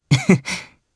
Theo-Vox_Happy1_jp_b.wav